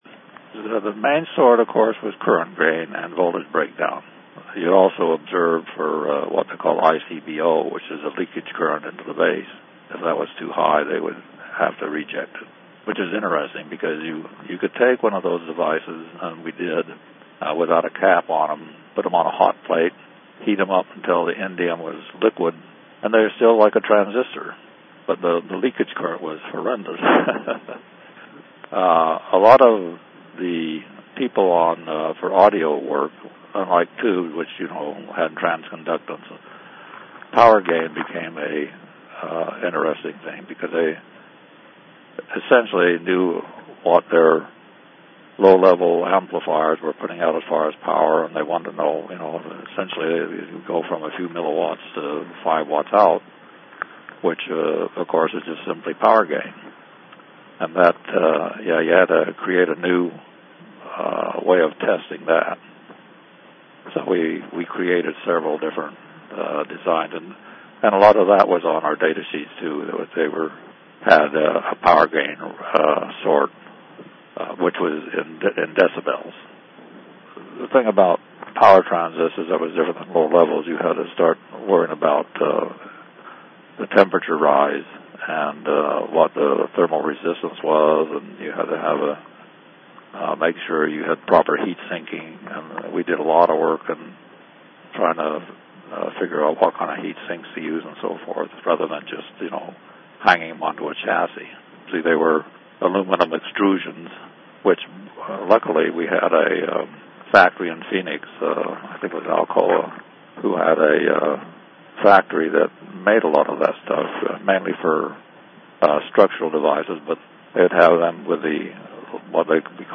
from a 2008 Interview with